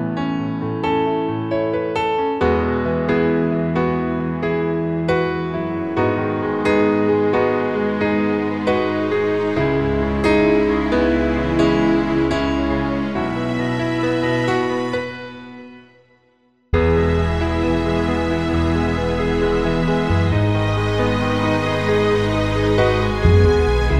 Up 5 Semitones For Female